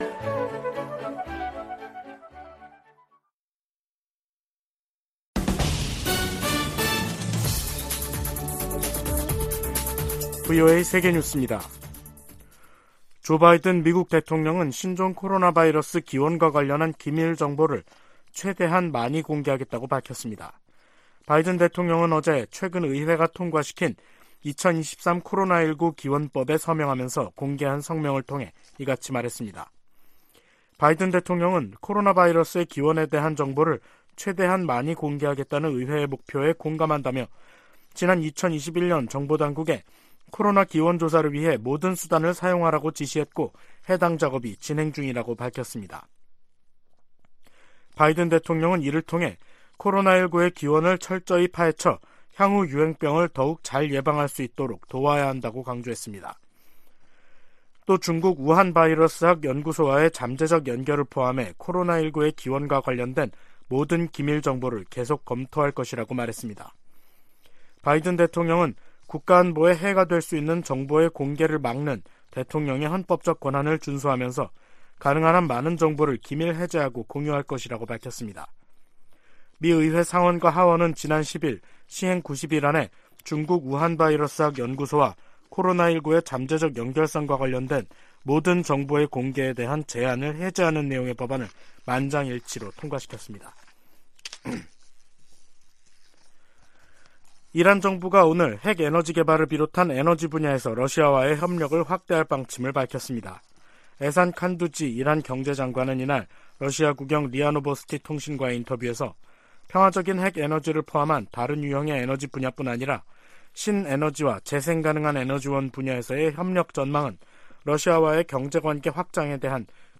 VOA 한국어 간판 뉴스 프로그램 '뉴스 투데이', 2023년 3월 21일 2부 방송입니다. 북한이 모의 핵탄두를 탑재한 미사일 공중폭발 시험훈련에 성공했다고 밝히면서 전술핵 위협이 한층 현실화했다는 평가가 나옵니다. 유엔 안전보장이사회가 북한의 대륙간탄도미사일(ICBM) 발사에 대응한 공개회의를 개최하고 북한을 규탄했습니다. 북한에서 살인과 고문, 인신매매 등 광범위한 인권 유린 행위가 여전히 자행되고 있다고 미 국무부가 밝혔습니다.